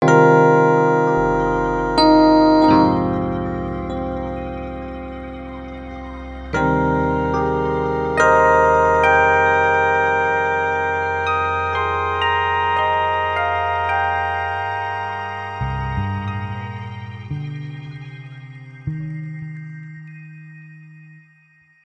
Vier verzamelingen van klanken zoals hij nog nooit gehoord had vloeide uit de schelp. Samen vormden ze een melodie die de visser liet overstromen met een nostalgisch gevoel.
Terwijl de mysterieuze klanken uitstierven, bleven ze galmen in zijn hoofd.